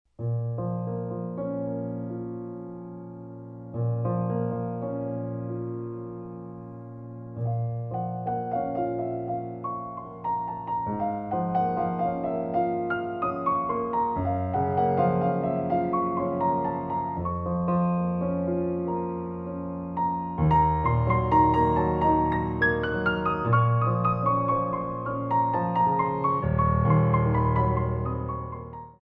Enchainement Sur Le Théme Révérence